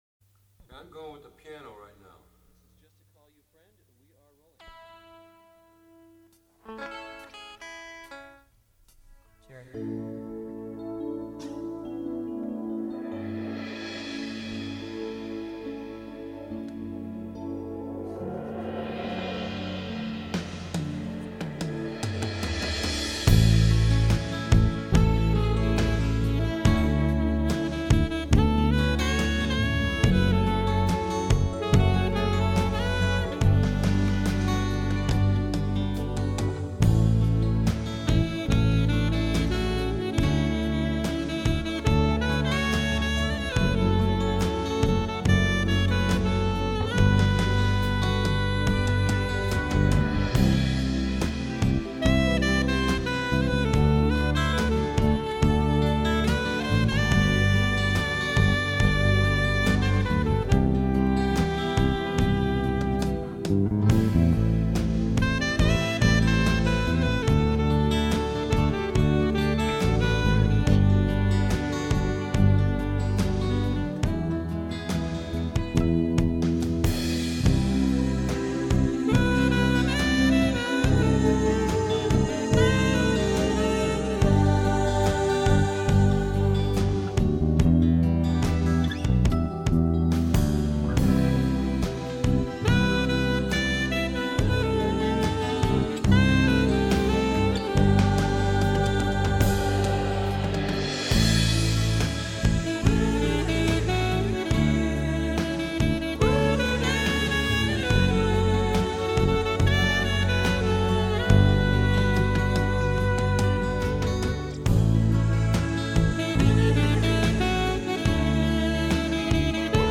piano/keyboard
bass/electric guitar
drums
acoustic guitar
background vocals